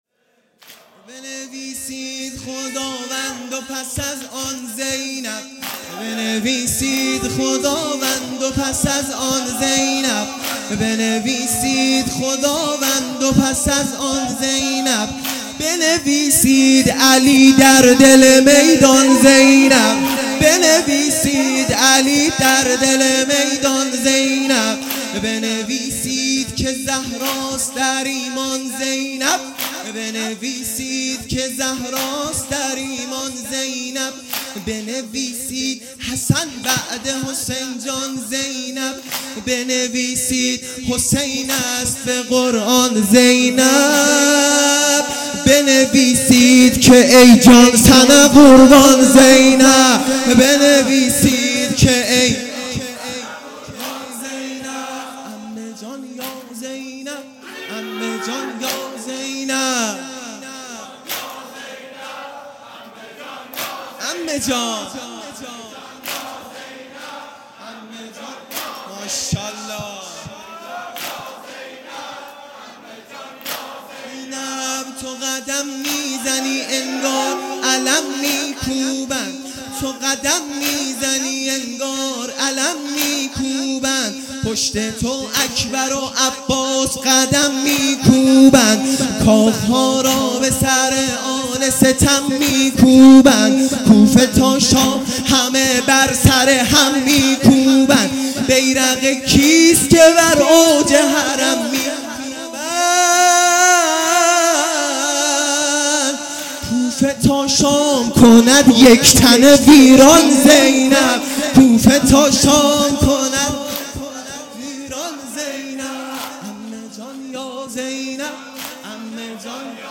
شب چهارم محرم